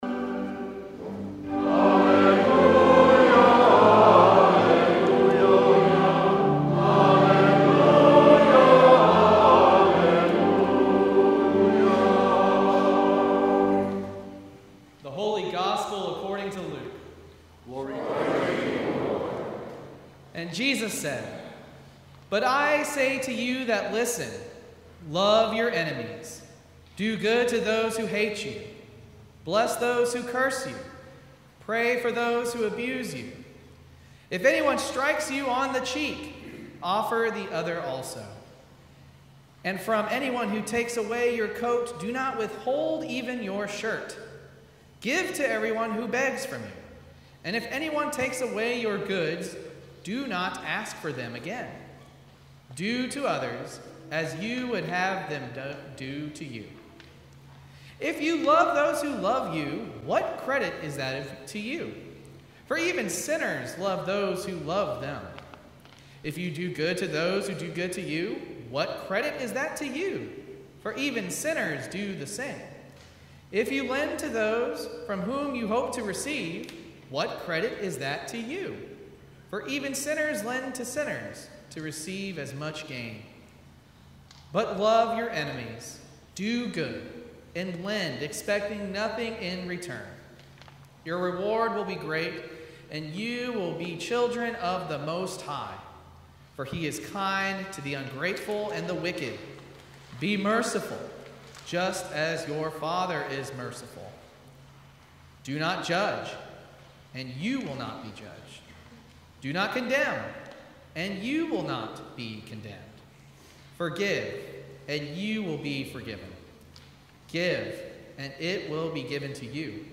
Sermon from Seventh Epiphany